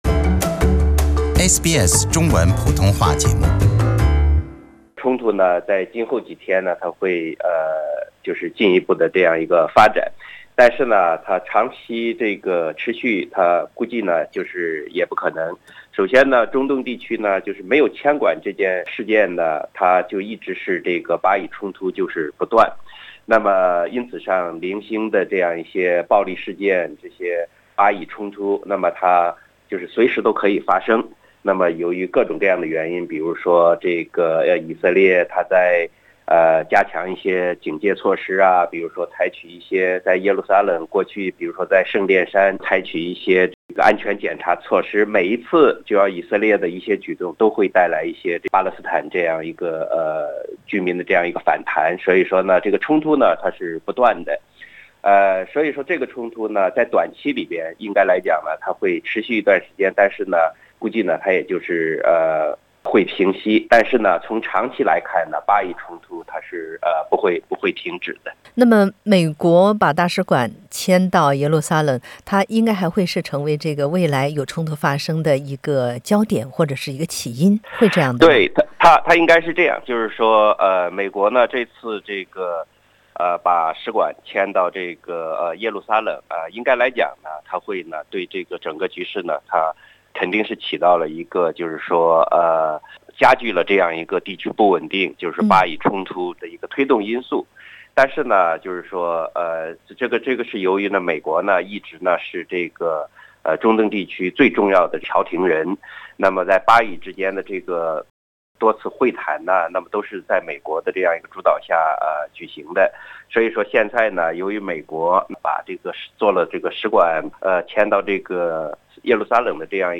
Palestinian protesters are in action during clashes after protests near the border with Israel on the eastern Gaza Strip Source: AAP 巴以是否有和平的希望？迁移使馆是否还会继续成为冲突导火索？ 请点击收听采访录音。